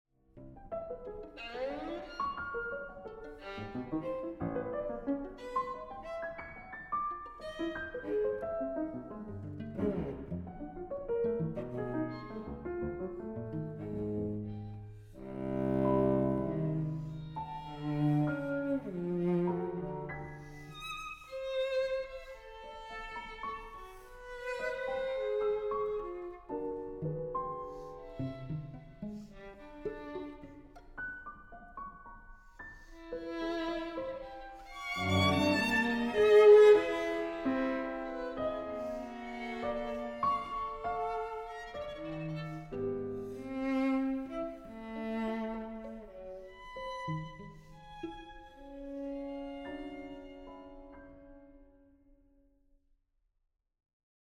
Recording: Festeburgkirche Frankfurt, 2024